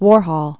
(wôrhôl, -hōl), Andy 1928-1987.